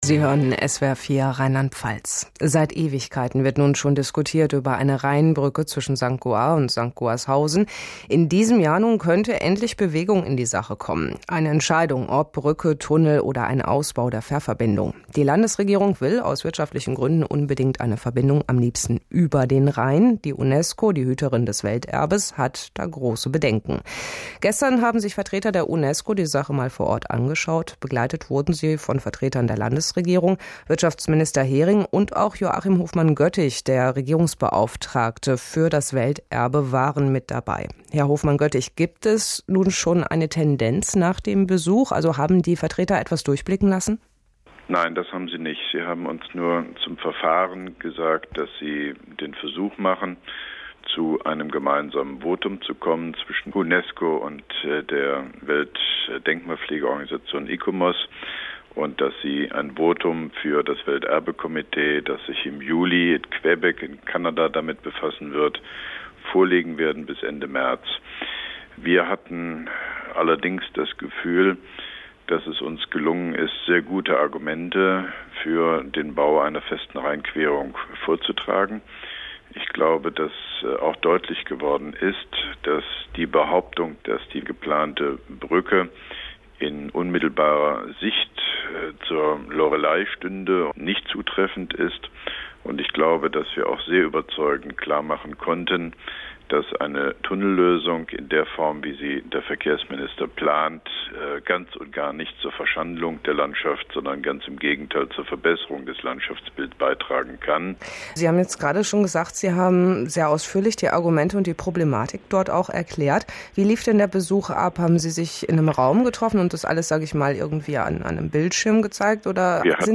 UNESCO_-_Interview_SWR_4.MP3